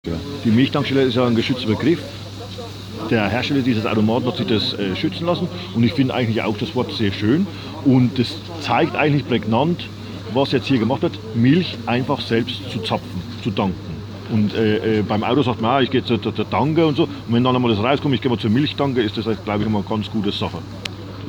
Großer Andrang am Hofladen der Agroprodukt Sonneberg am Gelände Rohof.